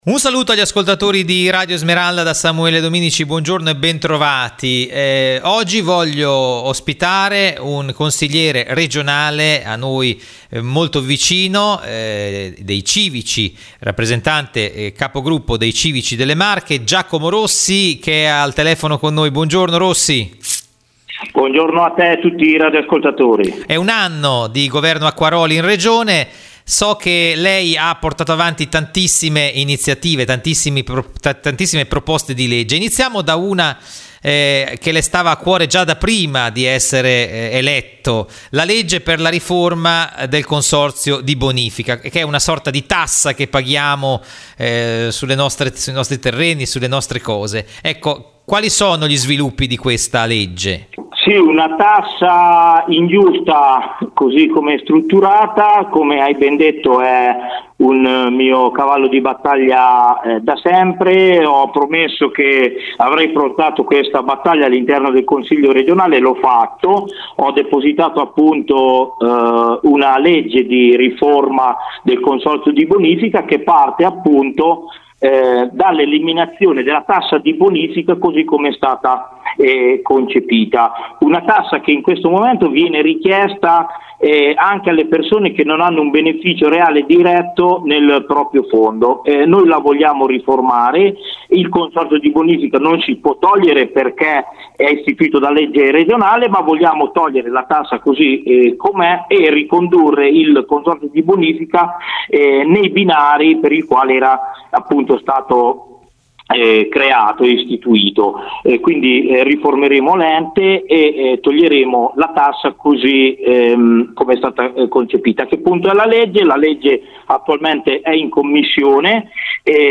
CIVICI MARCHE: IL CONSIGLIERE REGIONALE GIACOMO ROSSI AI NOSTRI MICROFONI